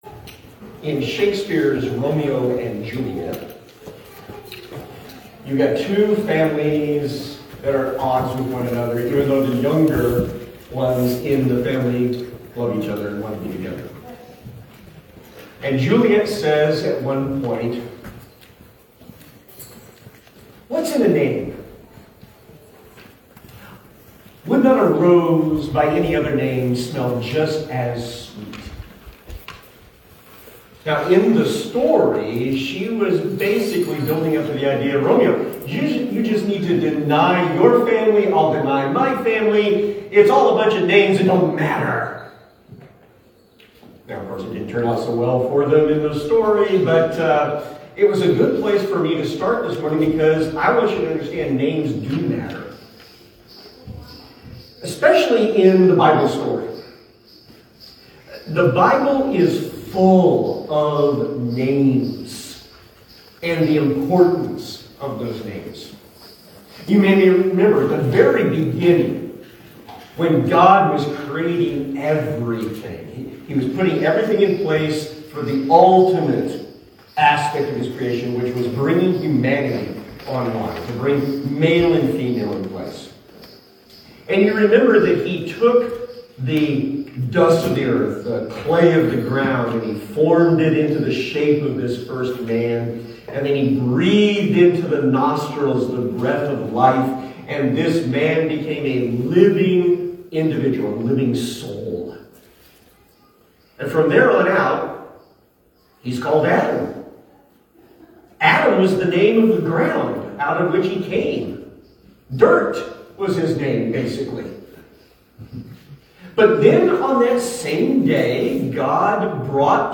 Bible, Bible Study, Christian, Christian Teaching, Church, Church Sermon